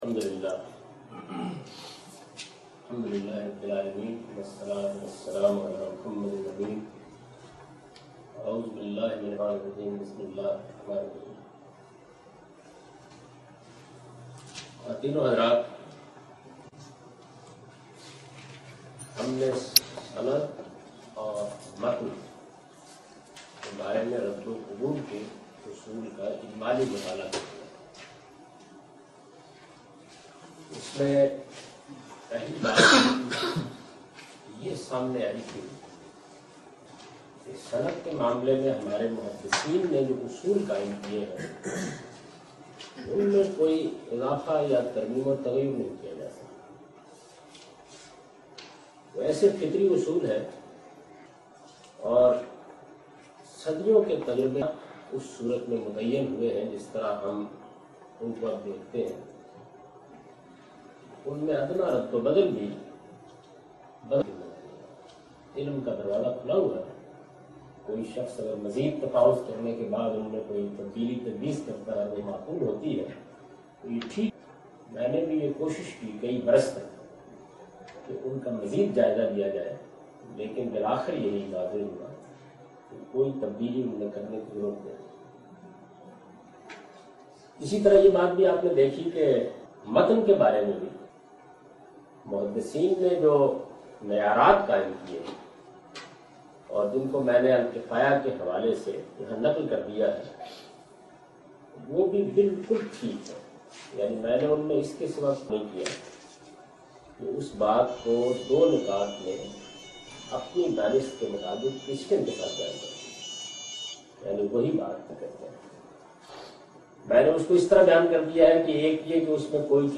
A comprehensive course on Islam, wherein Javed Ahmad Ghamidi teaches his book ‘Meezan’.
In this lecture series, he not only presents his interpretation of these sources, but compares and contrasts his opinions with other major schools developed over the past 1400 years. In this lecture he teaches the fundamental principles to understand Hadith.